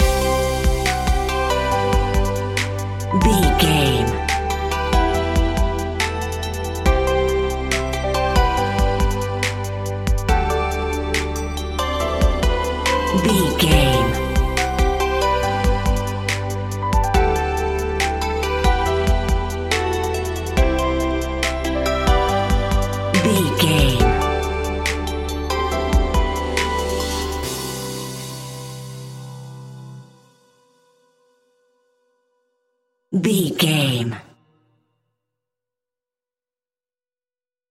Ionian/Major
Slow
chilled
laid back
hip hop drums
hip hop synths
piano
hip hop pads